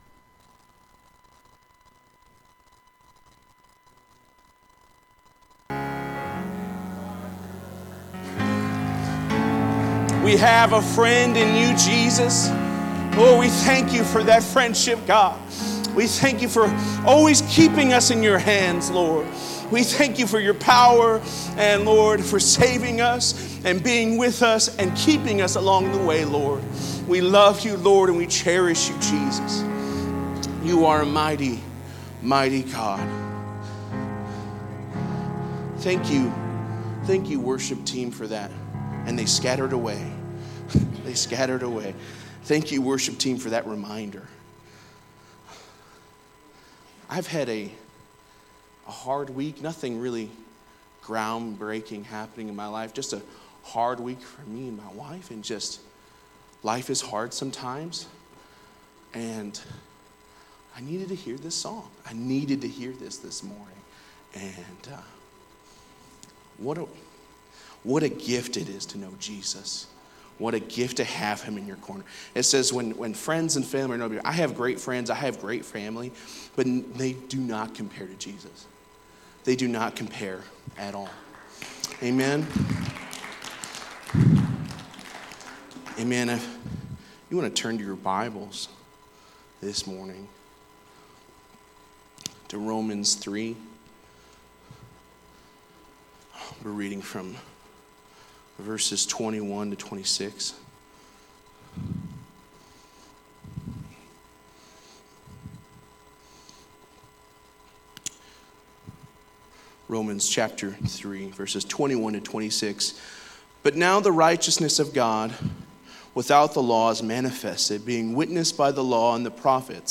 Sermons | Elkhart Life Church
Sunday Service - Part 16